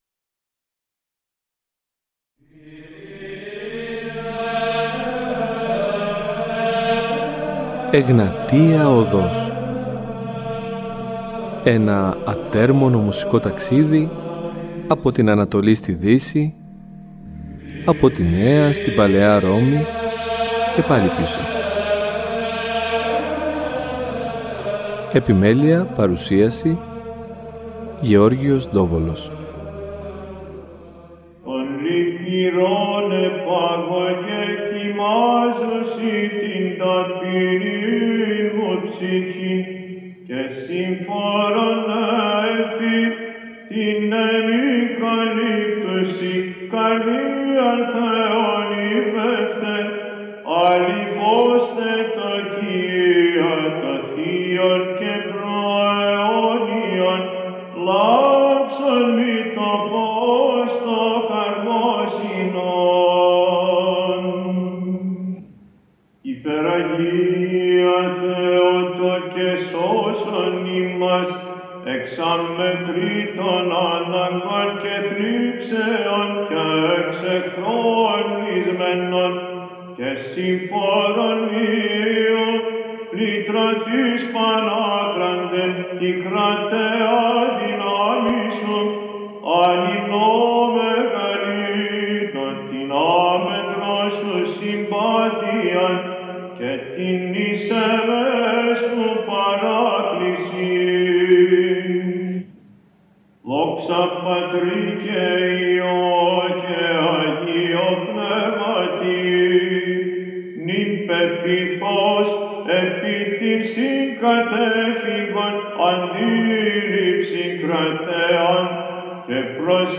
Με αφορμή τα 100 χρόνια από τη Μικρασιατική Καταστροφή η εκπομπή ιχνηλατεί συνθέτες αλλά και ερμηνευτές, οι οποίοι άφησαν αξιόλογο έργο αλλά και επηρέασαν με τρόπο διαδραστικό την μουσική δημιουργία στην εποχή τους. Όλα τα παραπάνω ζωντανεύουν με οδηγό την γλαφυρή αφήγηση
σε μια μοναδική συνέντευξη στο Τρίτο Πρόγραμμα της ΕΡΑ